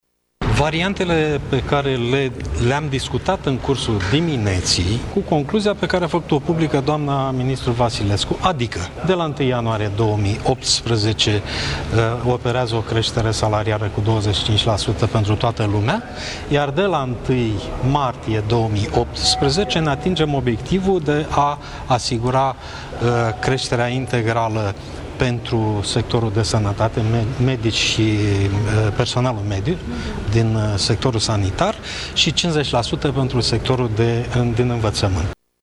La rândul său, ministrul finanțelor, Viorel Ștefan, declară că majorările cu 25 % pentru toți bugetarii se vor face de la 1 ianuarie 2018: